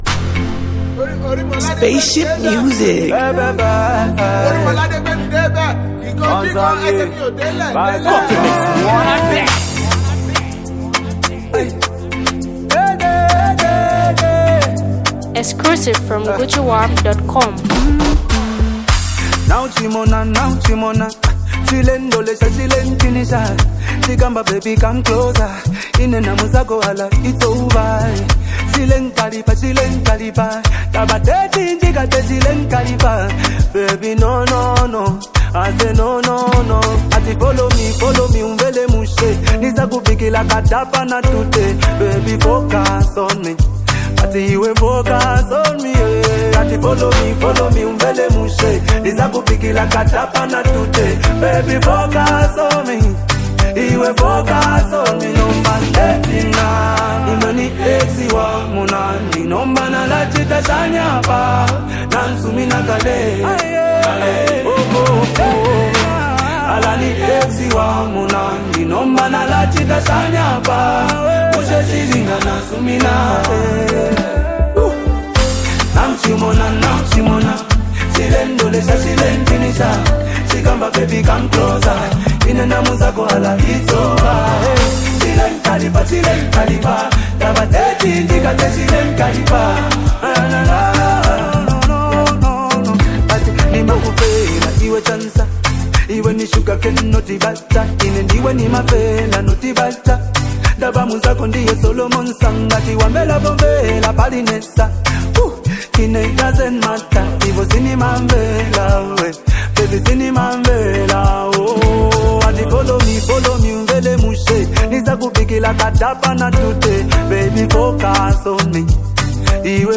This infectious track
His soulful voice weaves a narrative of love and heartache